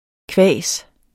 Udtale [ ˈkvæˀs ]